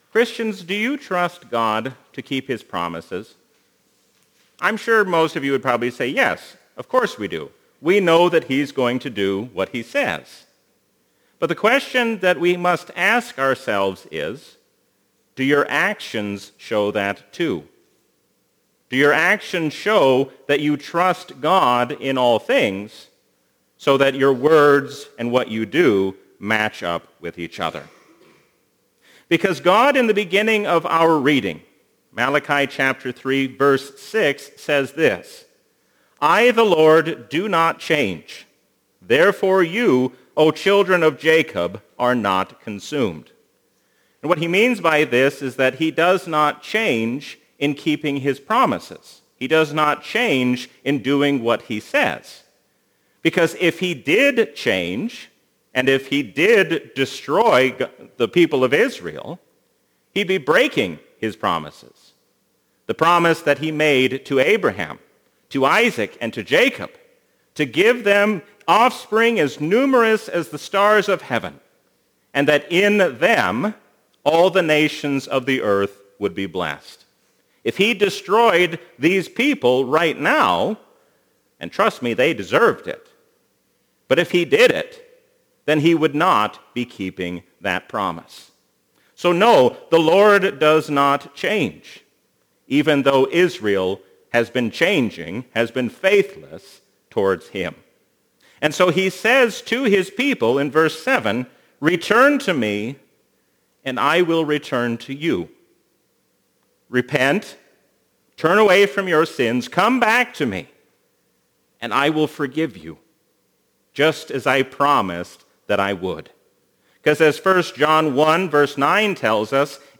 A sermon from the season "Trinity 2021."